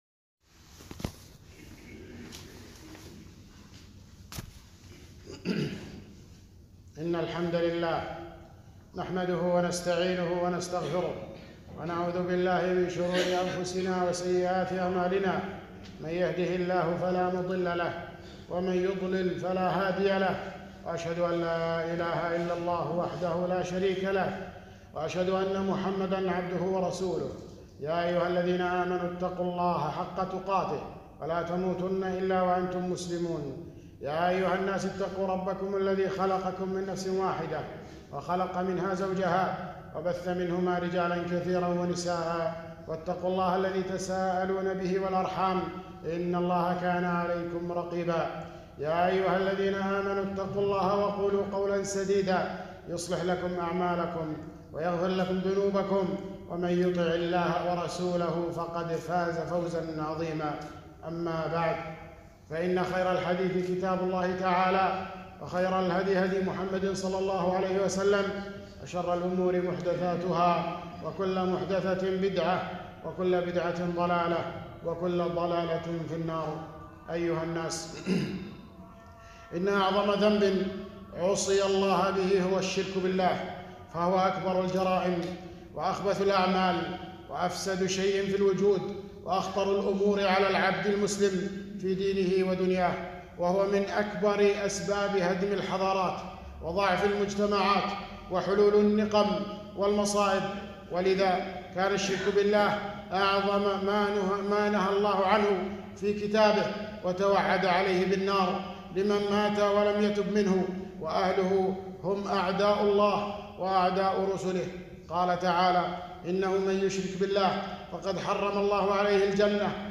خطبة - خطورة الشرك والتحذير منه